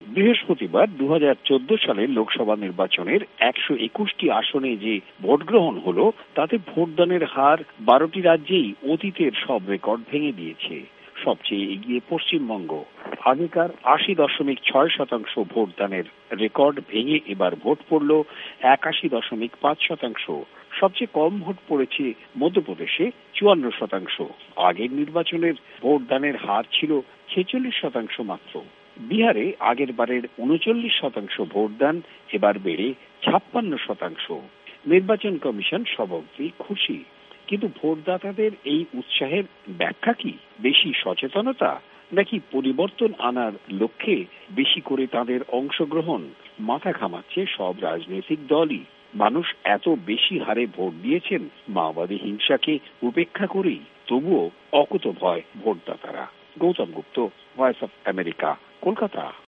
ভয়েস অব আমেরিকার কোলকাতা সংবাদদাতাদের রিপোর্ট